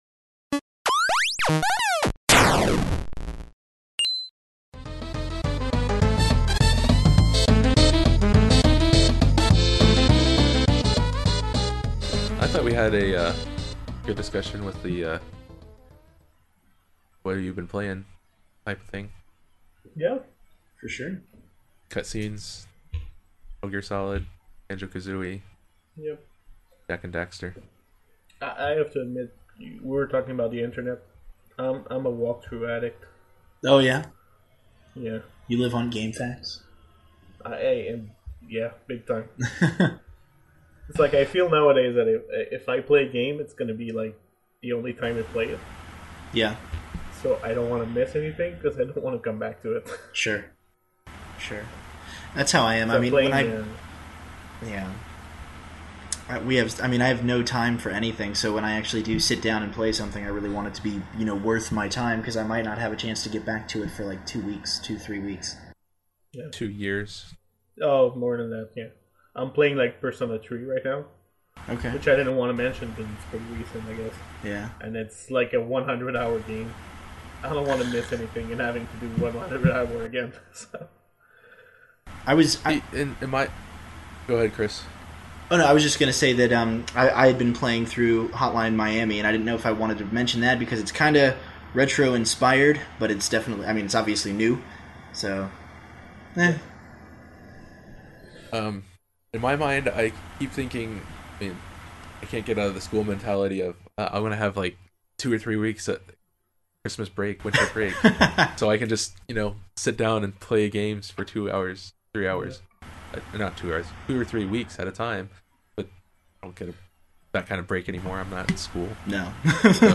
Keep in mind there’s no real structure and the audio quality wasn’t tweaked as much.